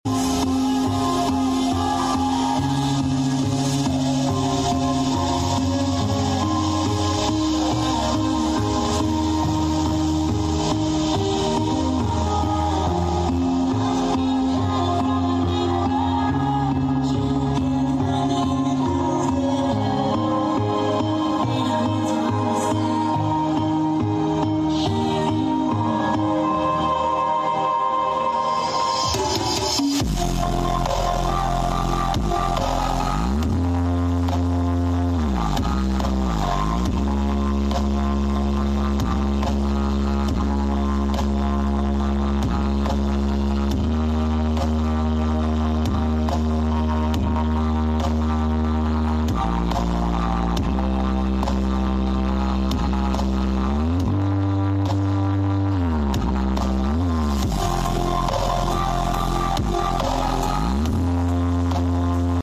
Brewog Tampil Mewah Karnaval Pucangarum